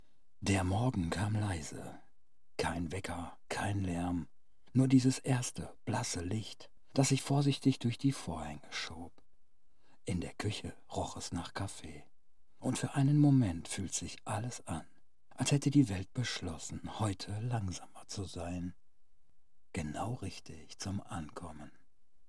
Literarische Lesung
Studio-quality recordings.